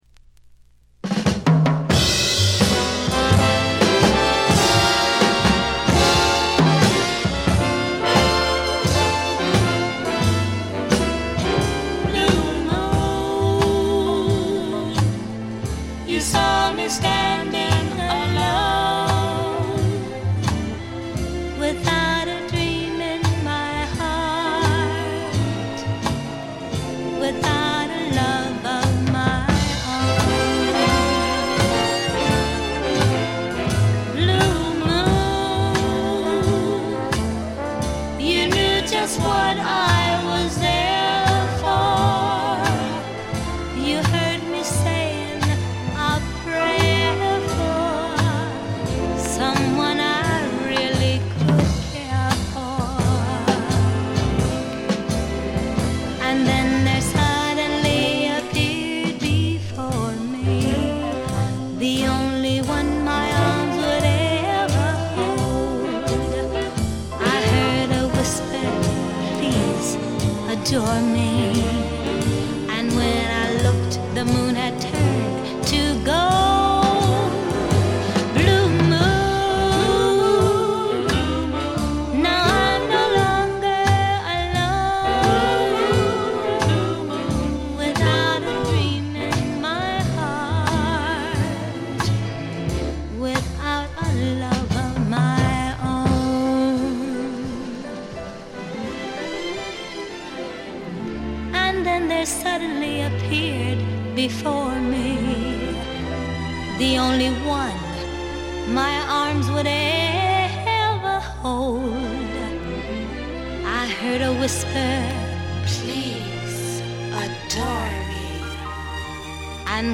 ほとんどノイズ感無し。
モノプレス。
試聴曲は現品からの取り込み音源です。